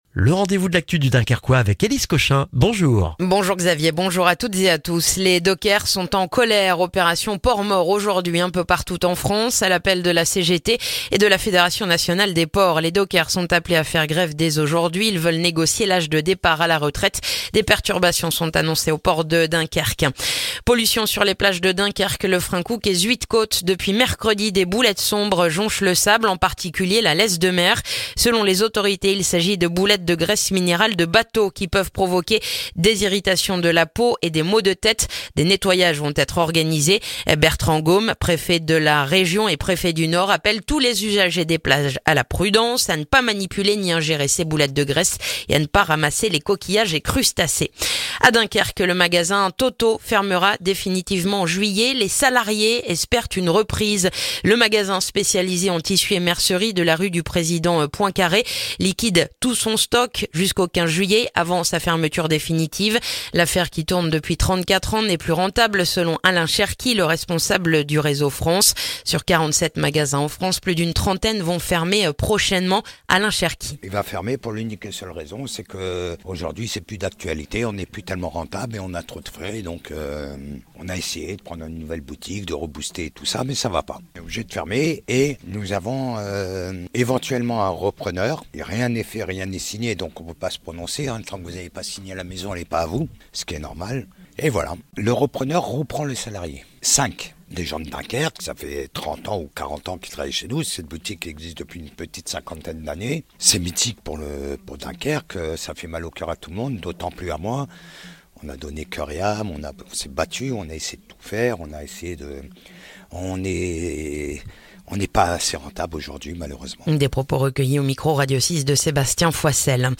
Le journal du vendredi 7 juin dans le dunkerquois